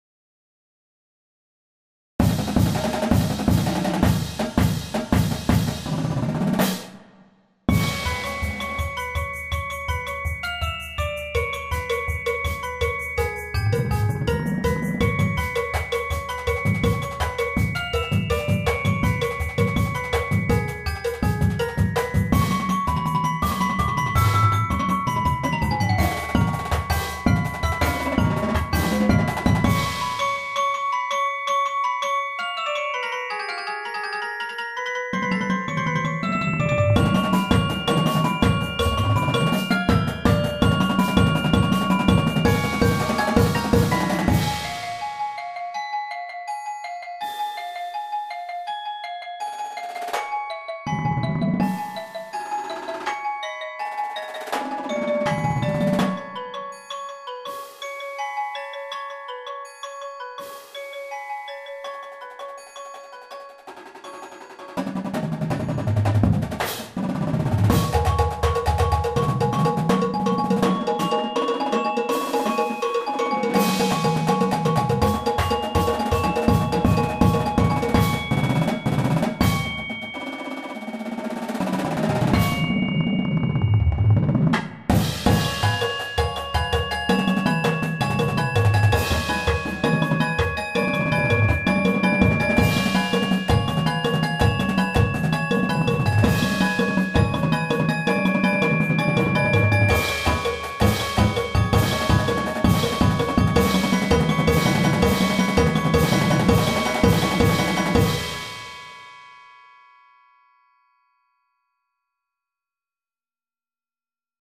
Keeping an aggressive, dissonant, and fast feel throughout
Snares
Tenors (Quints)
Bass Drums (5)
Marching Cymbals
Bells 1, 2
Xylophone 1, 2
Triangle
Cowbell
Shaker